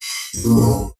drone2.wav